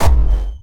poly_explosion_blackhole.wav